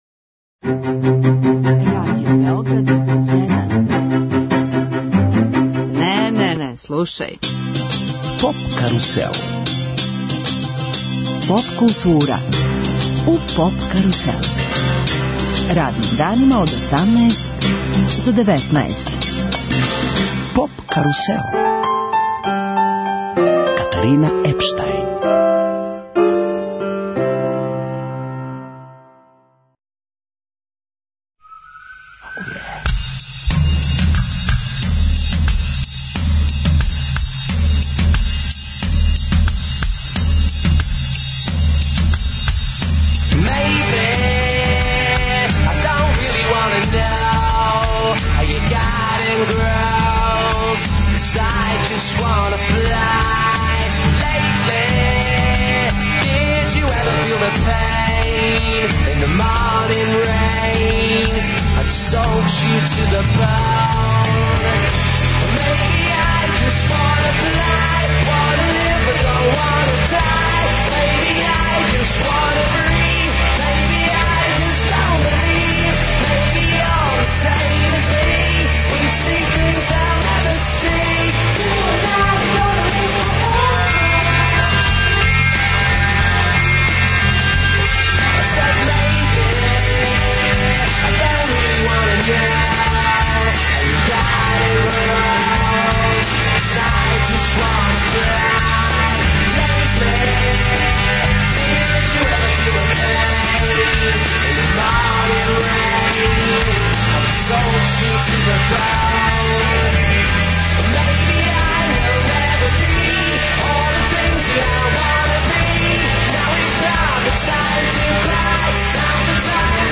Емисију реализујемо уживо из Новог Сада, са EXIT фестивала који је у знаку прославе 50 година од чувеног 'Лета љубави 1967'.